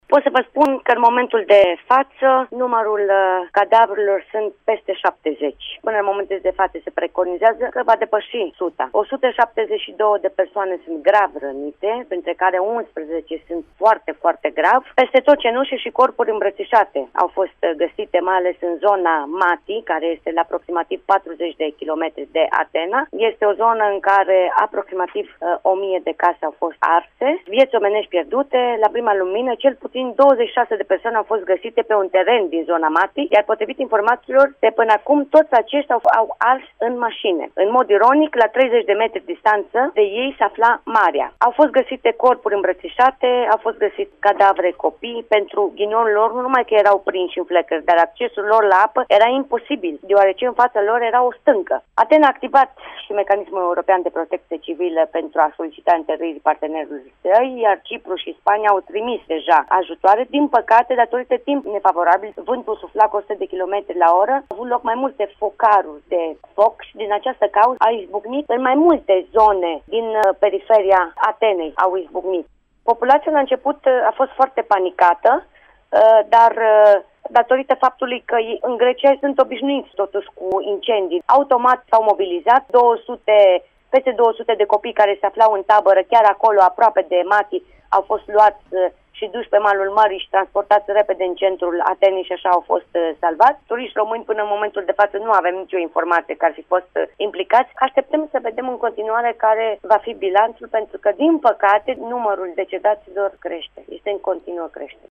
am aflat că au reuşit să depăşească zona afectată” a transmis, din Grecia, pentru Radio Reşiţa